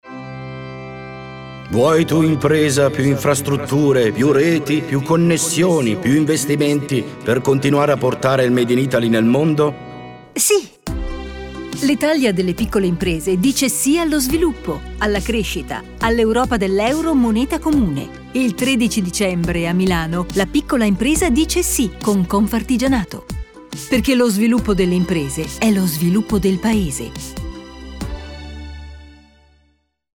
Spot_radio_Confartigianato_Quellidelsi2.mp3